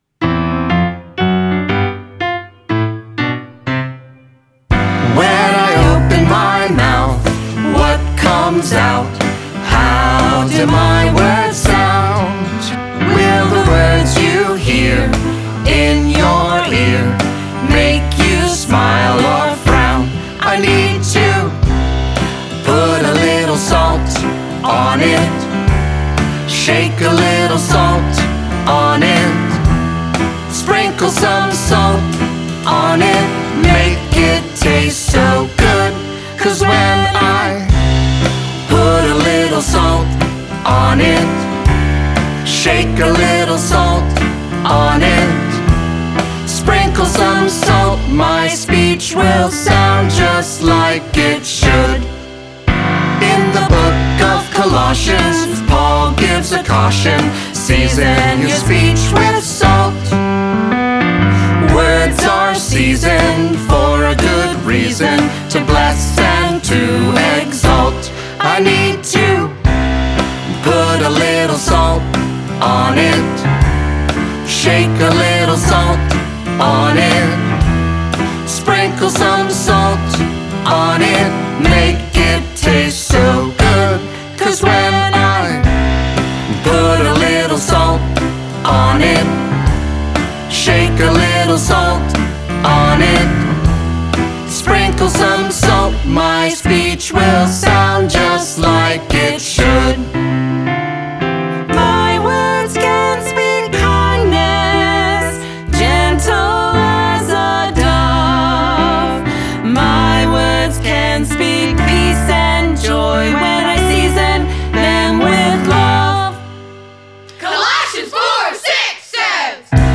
vocals, guitar, banjo, harmonica
upright bass
percussion
piano, trumpet, flugelhorn